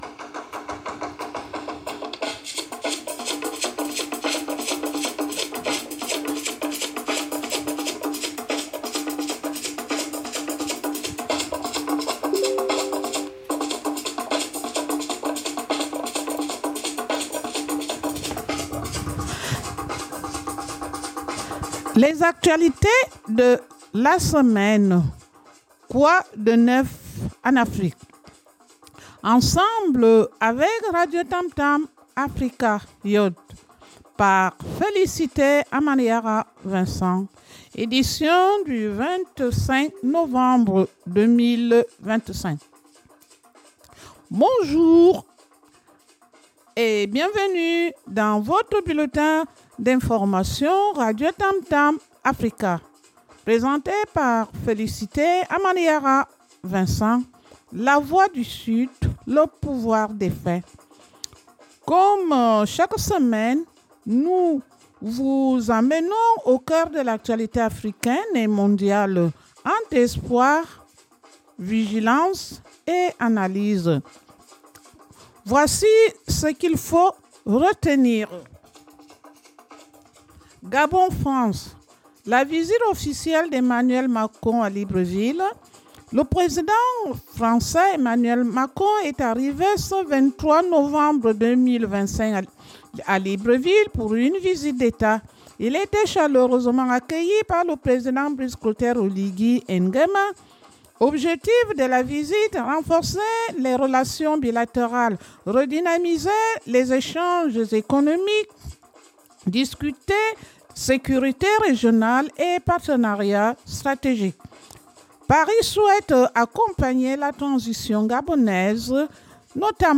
Radio TAMTAM AFRICA LES ACTUALITÉS DE LA SEMAINE – QUOI DE NEUF EN AFRIQUE ? BULLETIN D’INFORMATION – RADIOTAMTAM AFRICA 25 novembre 2025